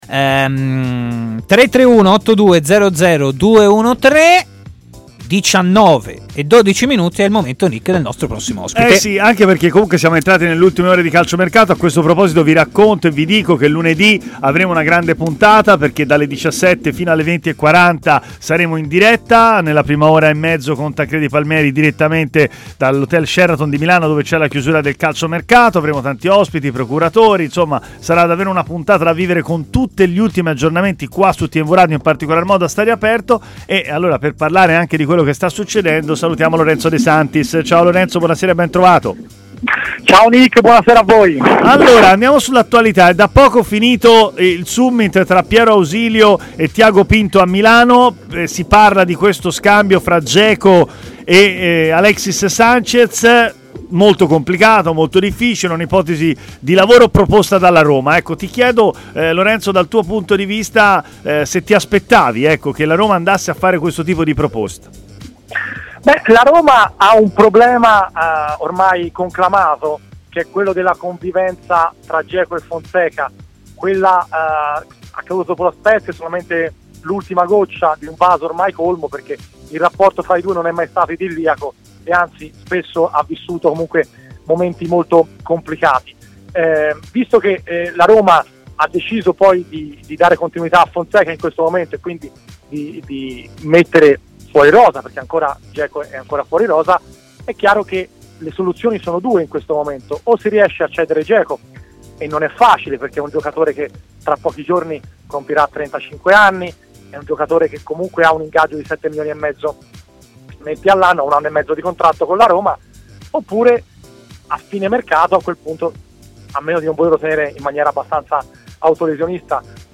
ha parlato a TMW Radio, nel corso della trasmissione Stadio Aperto